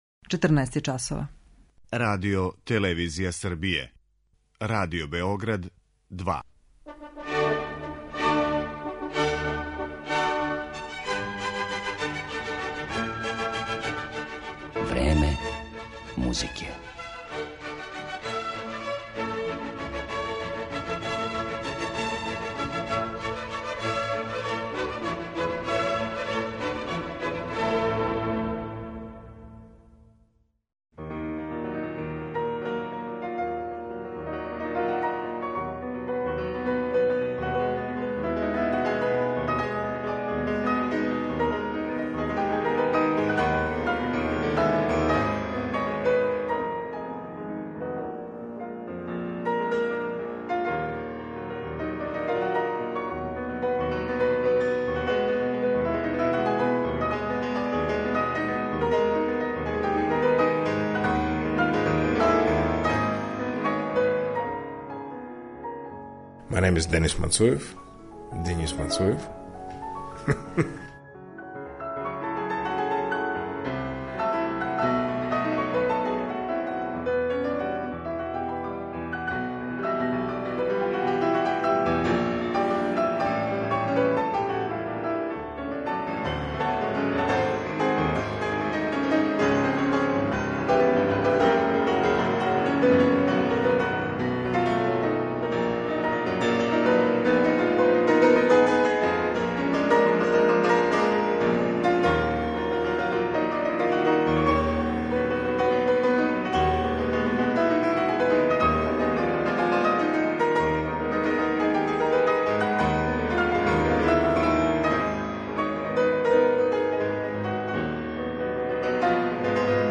Овом изузетном солисти посвећена је данашња емисија, у којој ћете моћи да чујете интервју остварен са њим непосредно пред наступ у Београду марта 2013. године.